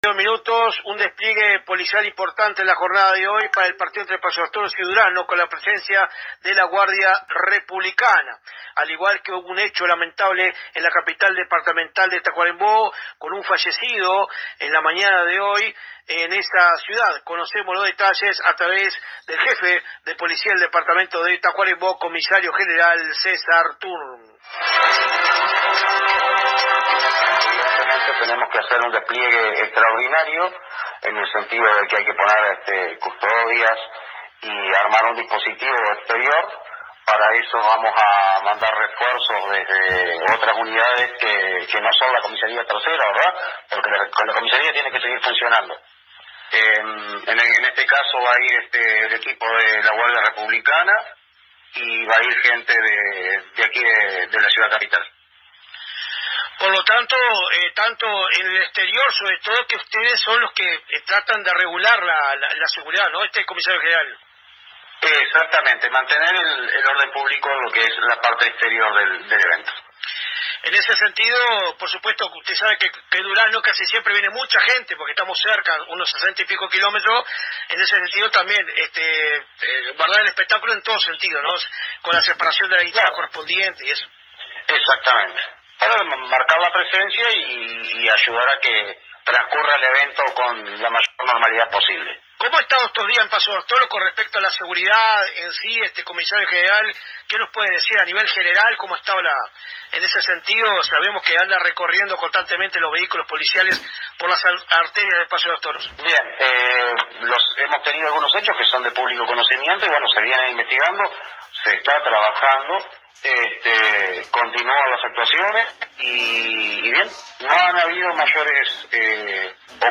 El Jefe de la Policía de Tacuarembó, Crio. Gral. César Tourn, conversó con los colegas de la AM 1110 de nuestra ciudad acerca del dispositivo de seguridad que será instalado la tarde y noche de hoy en los exteriores del Estadio Omar Odriozola, a fin de brindar seguridad al público y jugadores de los partidos entre las selecciones de Paso de los Toros y Durazno por la Copa Nacional del Interior OFI 2025.
Escuche al Crio. Gral César Tourn aquí: